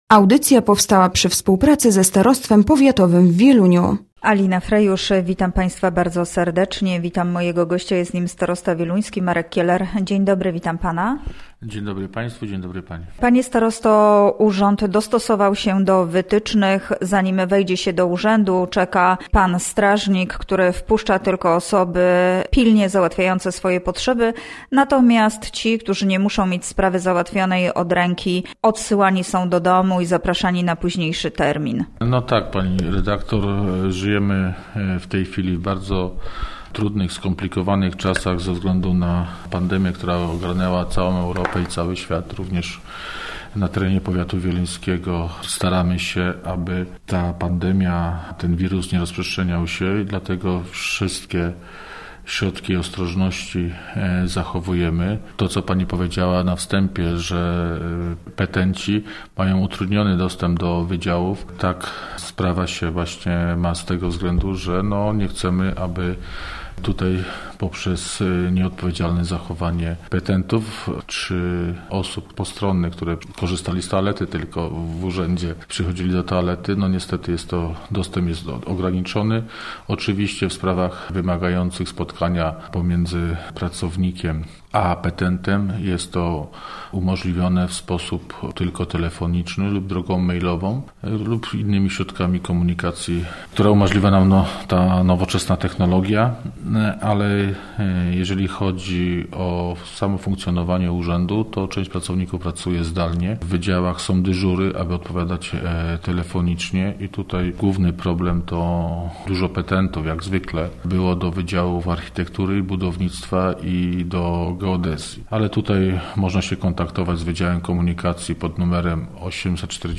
Gościem Radia ZW był Marek Kieler, starosta wieluński